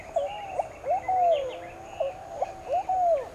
BARE-EYED PIGEON
To hear the distinctive sounds of the Bare-eyed Pigeon click here.
Bare-eyedPigeonAruba95.aif